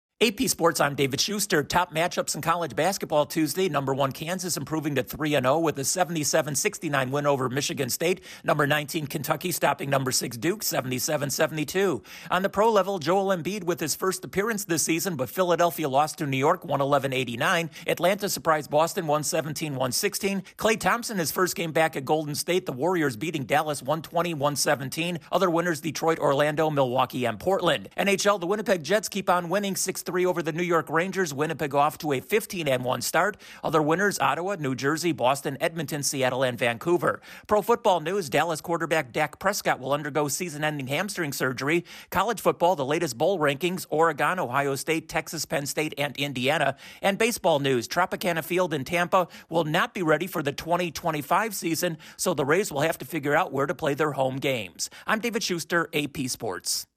A huge doubleheader in college basketball, a mild upset in the NBA, and where will the Tampa Bay Rays play their home games next season? Correspondent